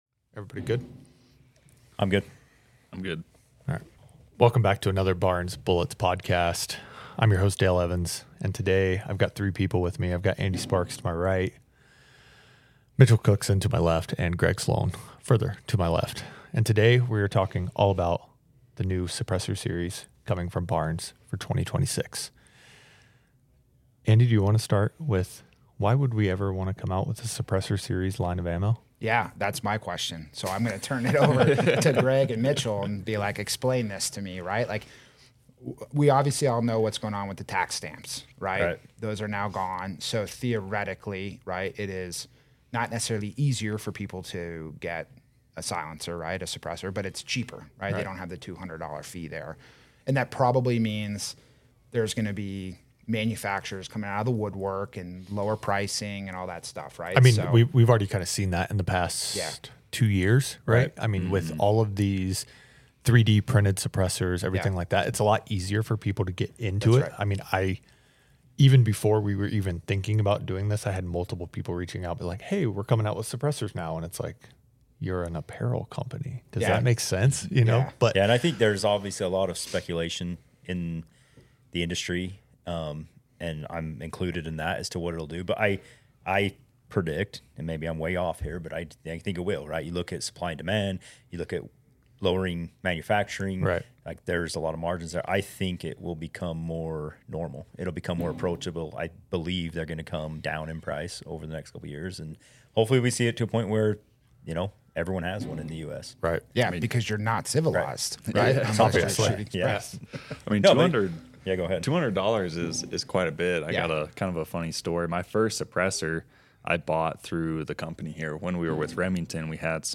This educational conversation dives into: